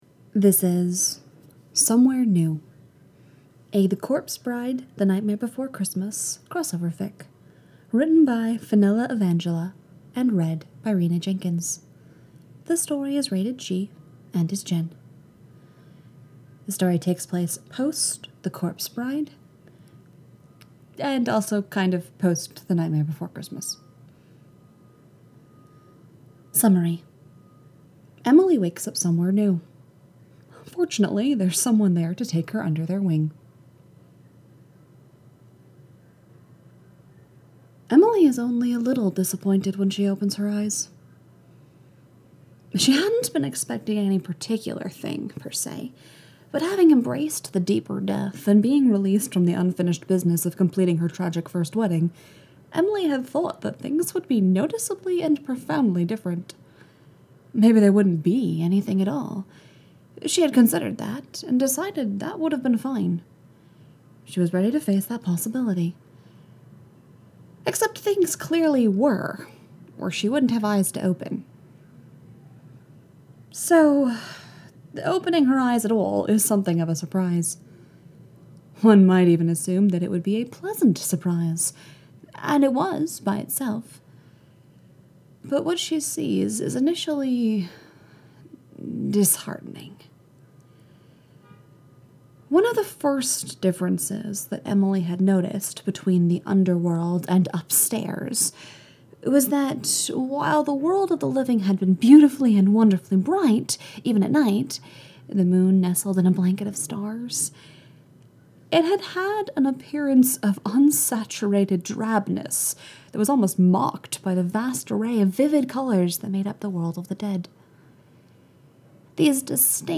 collaboration|ensemble